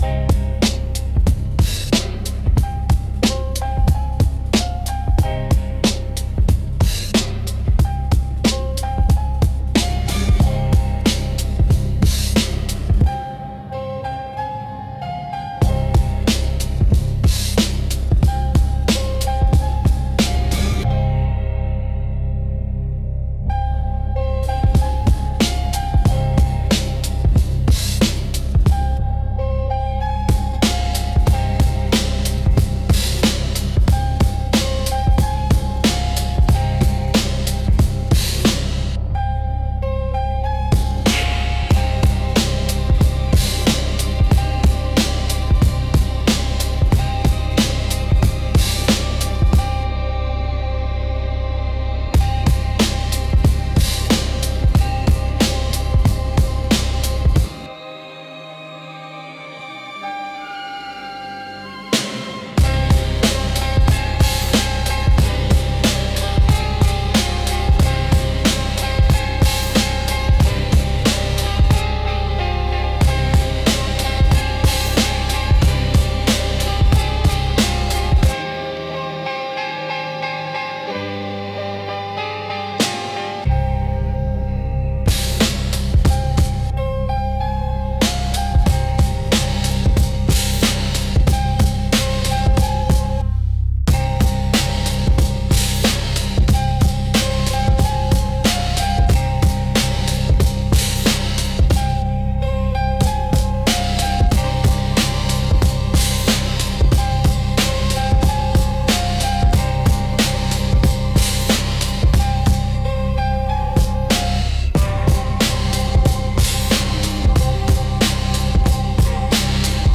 Instrumental - Curious Touch